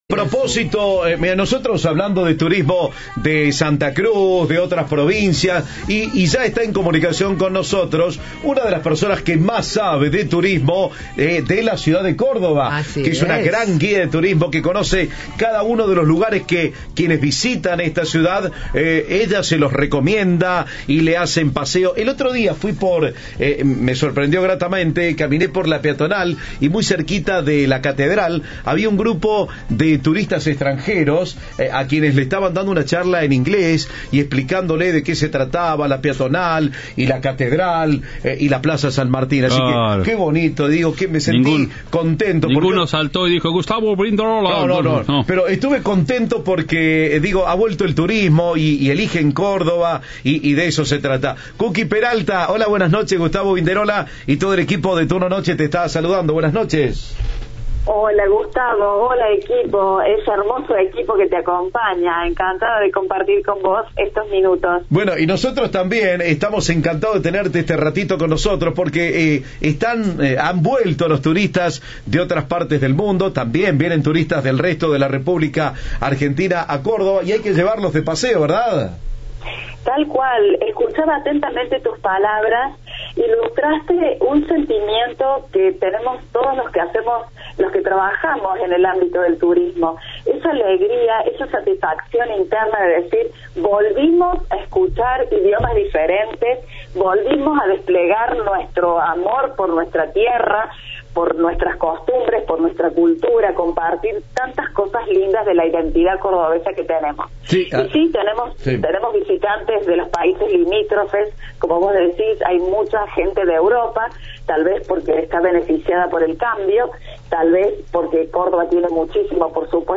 habló con Cadena 3 y brindó recomendaciones sobre los lugares que pueden visitarse en la capital provincial.